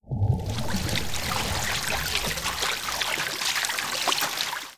WaterRipples.wav